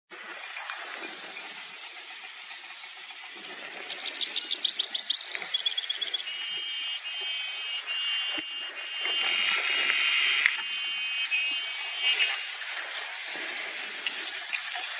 Caminheiro-grande (Anthus nattereri)
Audio logrado a traves del video, con mucho viento en el lugar, zona algo elevada.
Nome em Inglês: Ochre-breasted Pipit
Certeza: Fotografado, Gravado Vocal